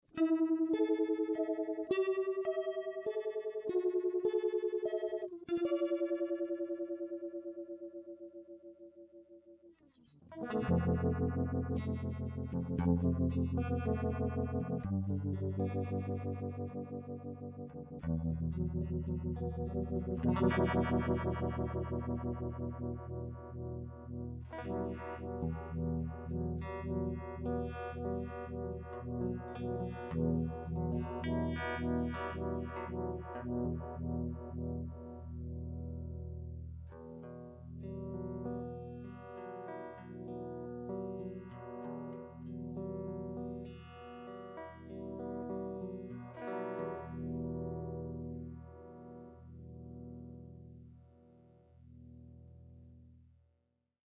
You'll notice how the vibrato clips are louder than the phasor ones - that's because I was lazy in my prototyping and didn't include attenuation on the vibrato outputs, normally they are level matched ...
MONO-mode Sound Clips
Test conditions: "Tribute" ASAT Classic Tele >> SV-2 >> EMU 1212 soundcard - of course it sounds a little different plugged into real amplifiers ... the clips are raw - there's no compression or verb ...
mono phasor sine2.mp3